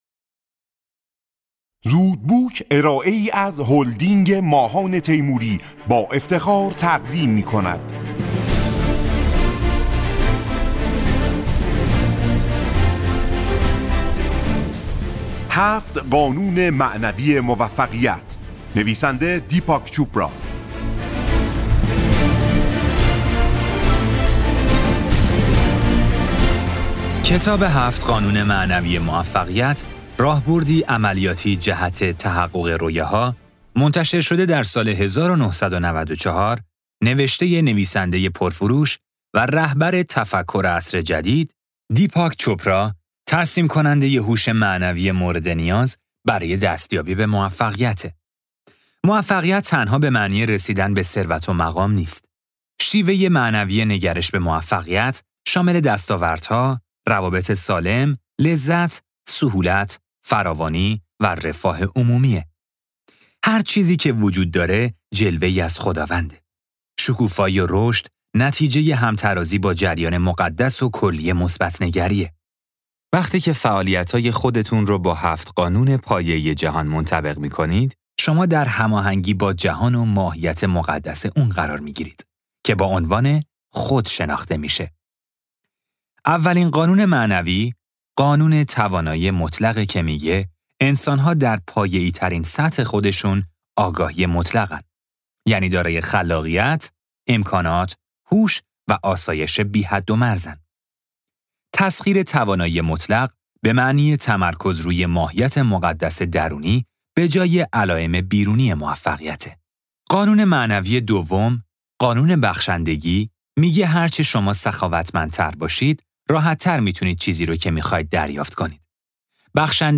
خلاصه کتاب صوتی هفت قانون معنوی موفقیت